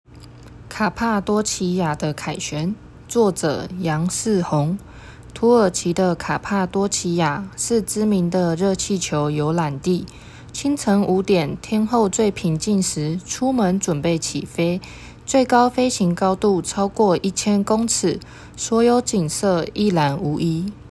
語音導覽，另開新視窗